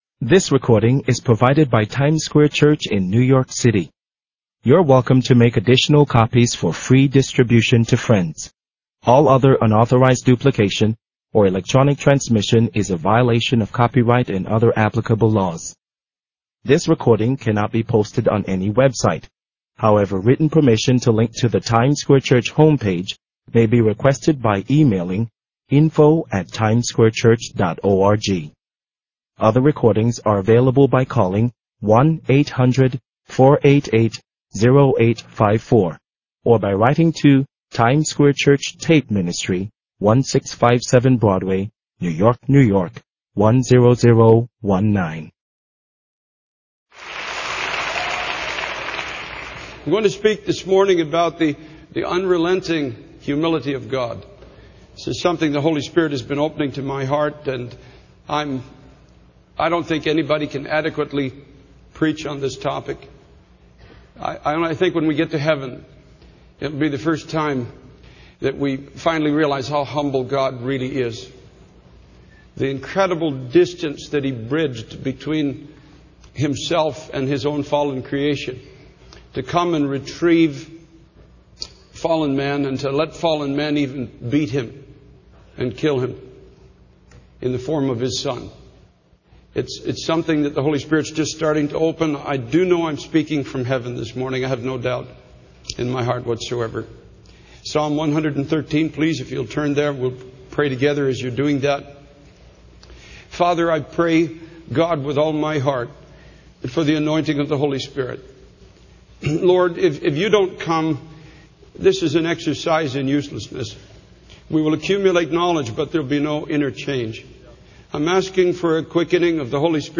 In this sermon, the preacher focuses on the scene of worship described in Revelation chapter 4.